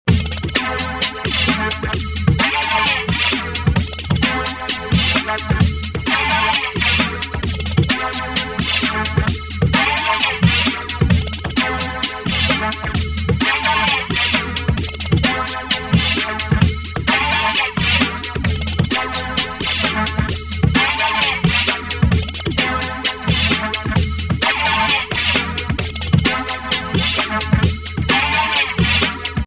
最初と最後がスムーズにつながるループ音。